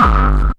HD BD 15  -L.wav